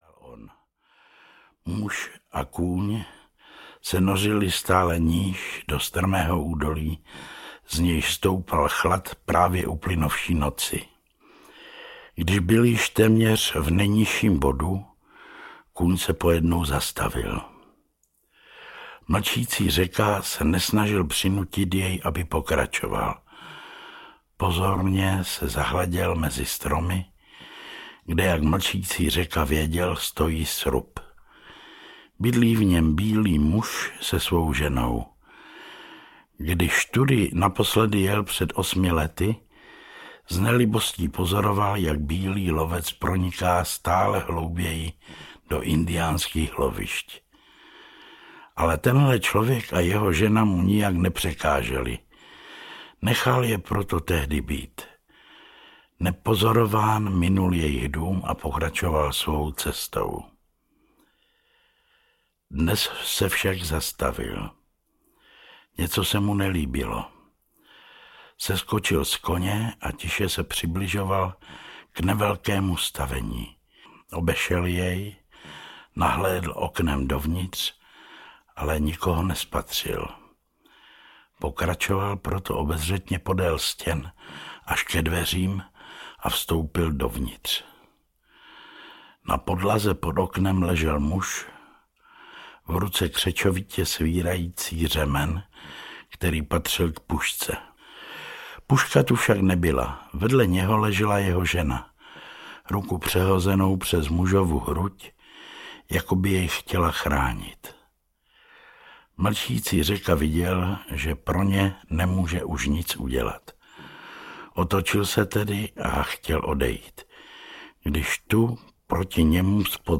Muž s bílým psem audiokniha
Novelu Muž s bílým psem s indiánskou tematikou písničkářka napsala jak ve formě tištěné, tak ve formě audioknihy, kterou načetl František Segrado a nově zbývající kapitoly sama Radůza.
Ukázka z knihy
• InterpretFrantišek Segrado, Radůza